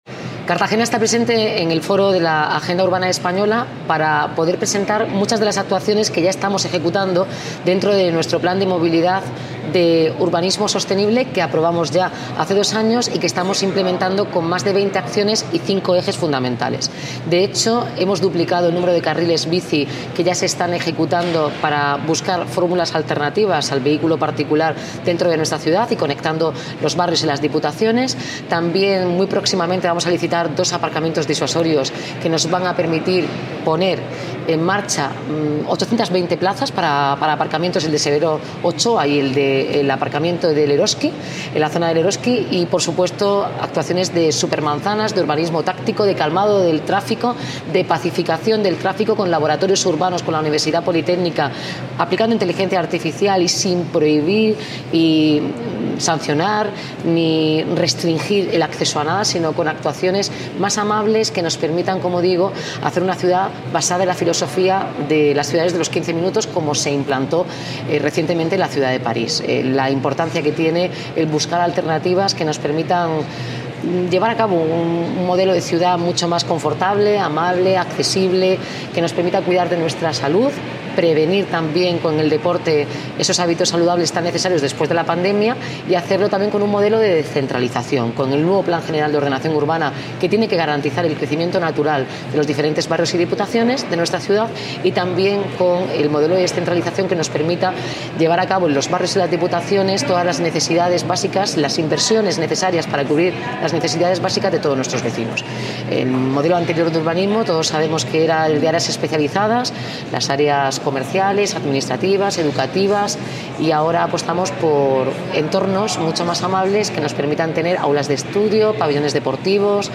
Enlace a Declaraciones de la alcaldesa de Cartagena sobre su participación en el II Foro Urbano de España celebrado en Granada